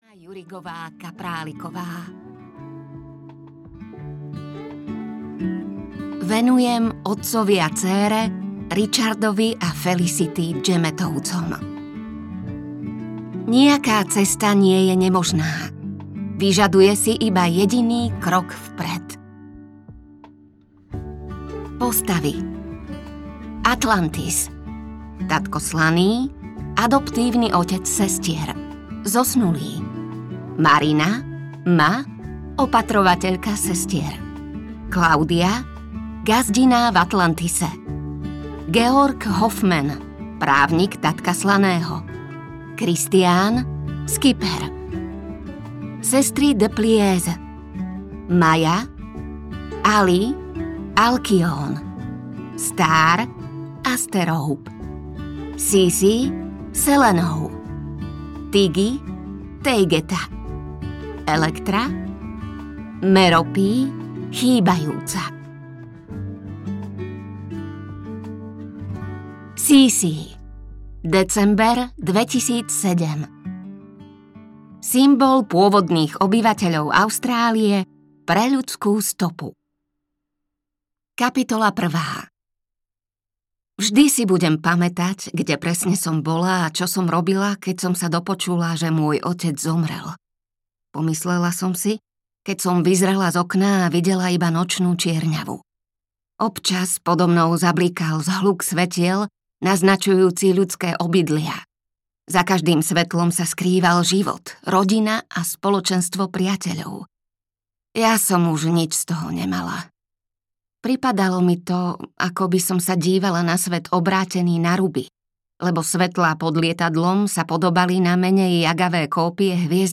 Perlová sestra audiokniha
Ukázka z knihy
perlova-sestra-audiokniha